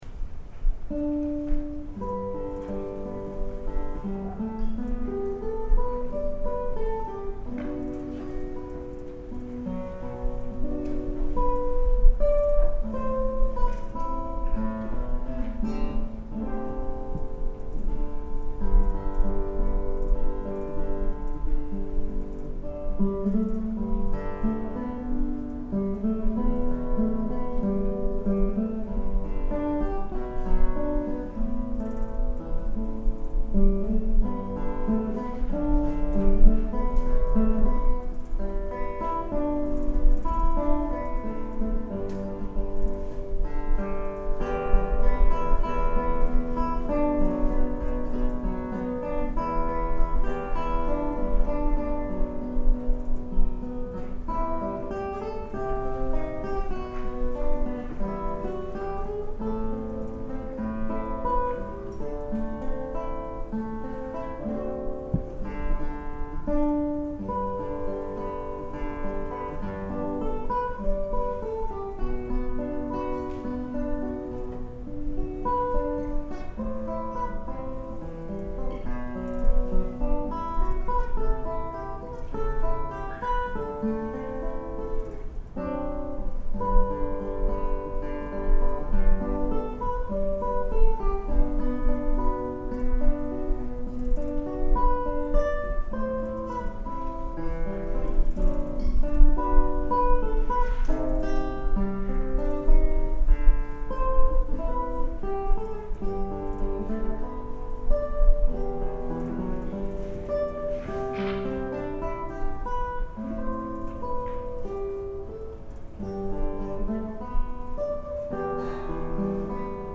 ギターコンサート
solo